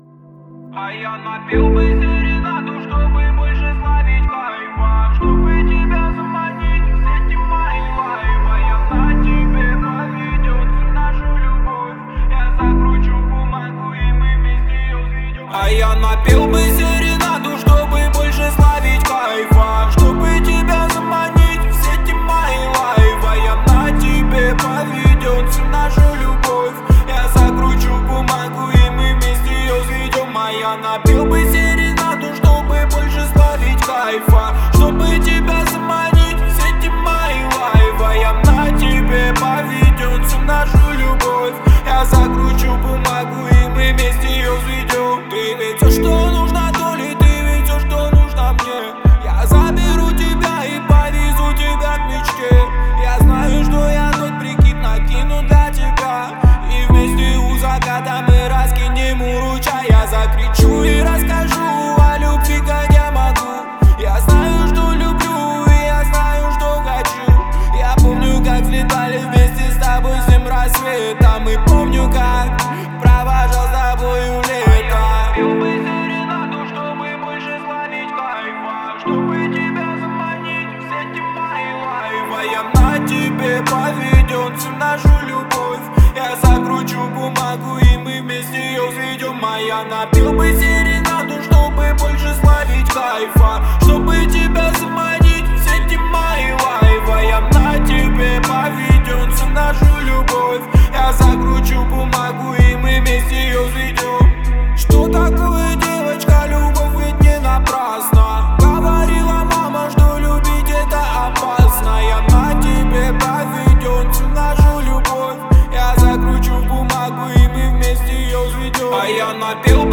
это мелодичный поп-трек с элементами фолка